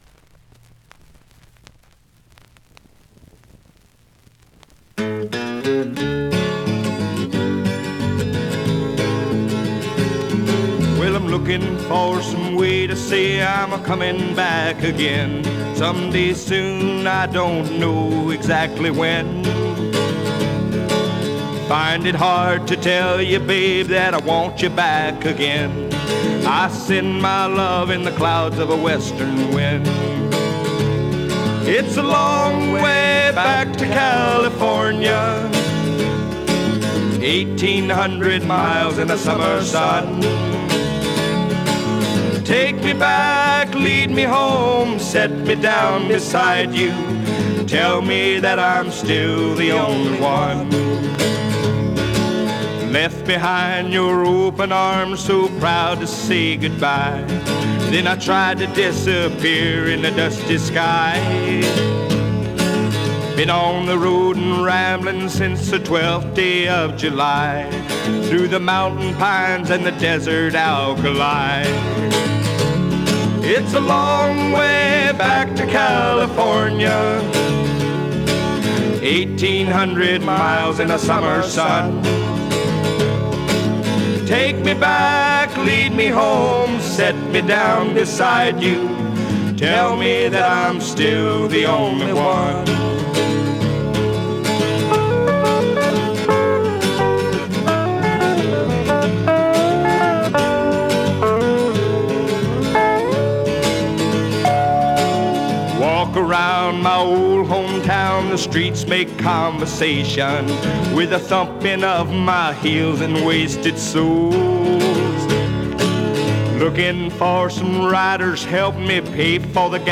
1970 Demo Album